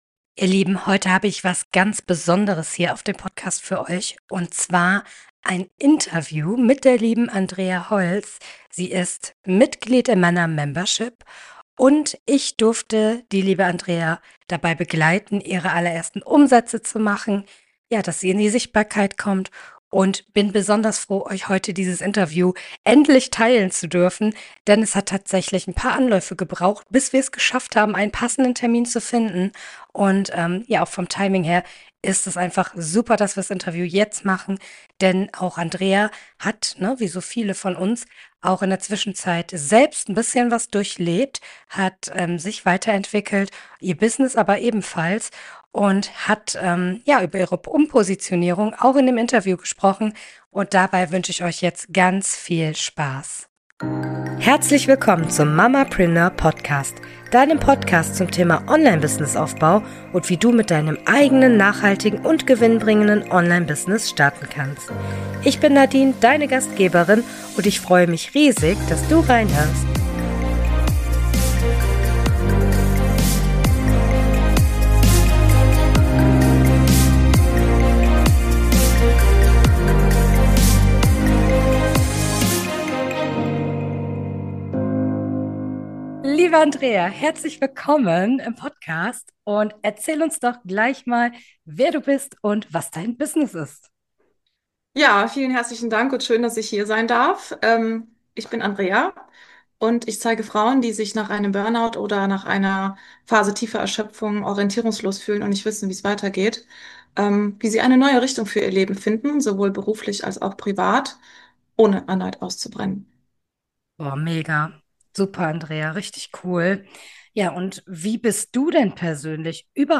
Beschreibung vor 7 Monaten In dieser Folge nehme ich Dich mit in das allererste Podcast-Interview